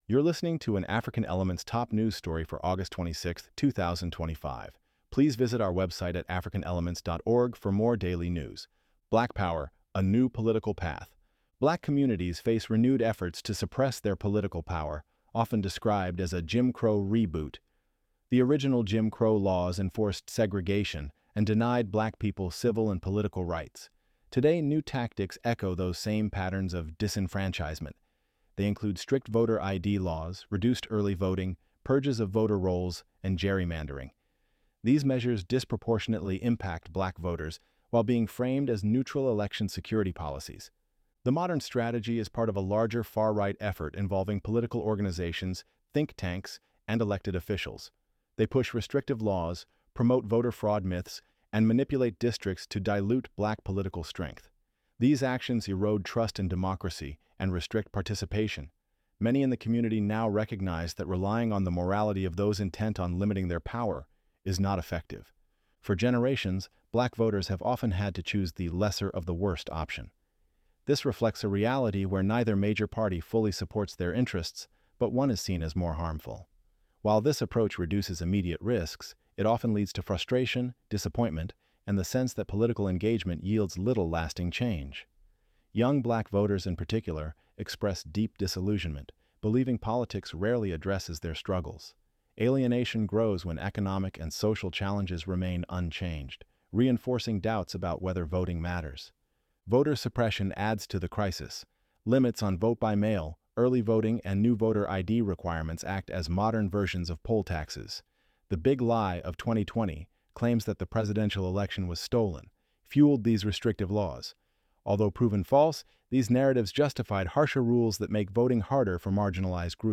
ElevenLabs_Black_Power_A_New_Political_Path.mp3